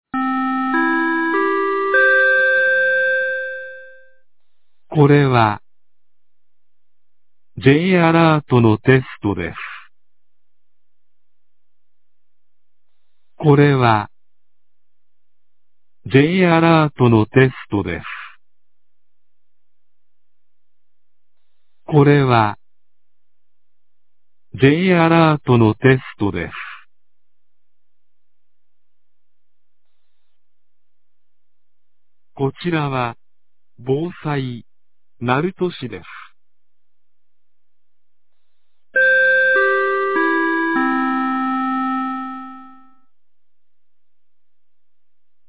防災行政無線放送 | 徳島県鳴門市メール配信サービス
2026年01月26日 09時01分に、鳴門市より大津町-木津野、里浦町-里浦、大麻町-池谷、大麻町-桧、撫養町-立岩、大津町-大代、撫養町-北浜、撫養町-林崎、大麻町-萩原へ放送がありました。